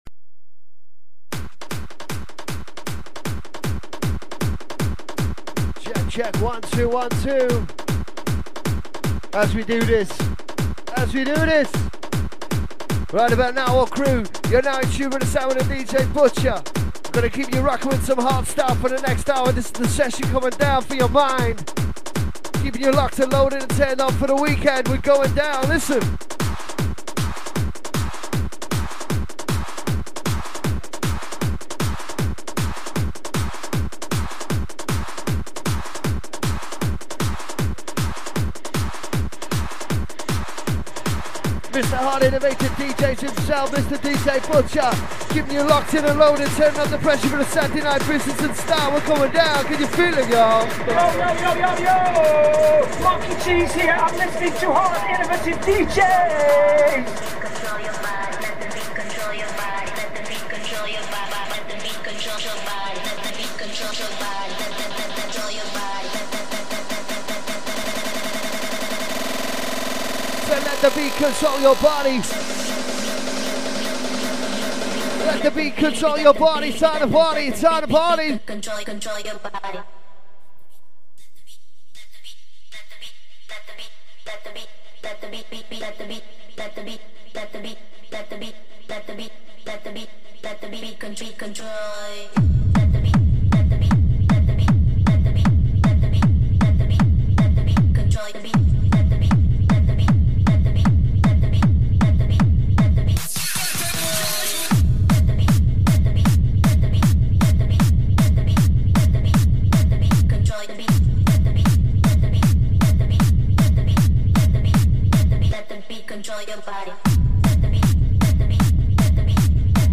to warm your mood right up and get you bouncing
Vocals Bounce Hardstyle